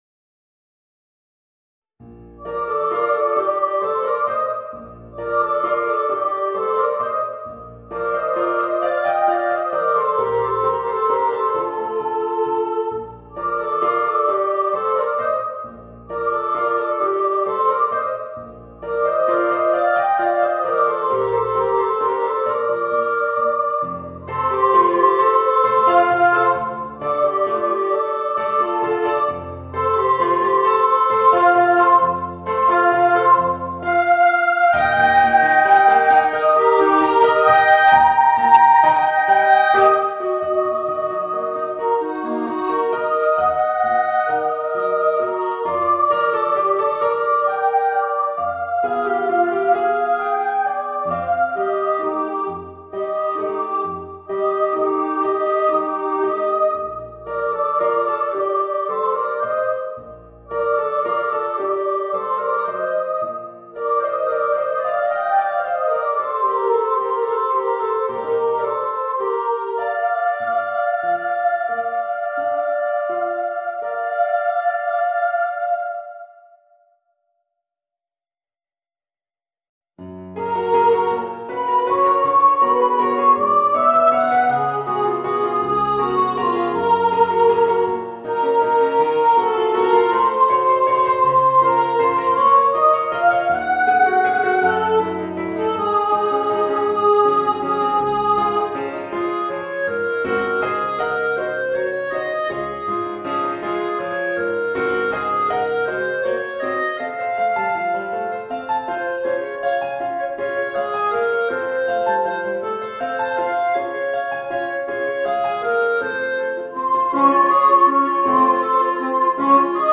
Voicing: Soprano, Clarinet and Piano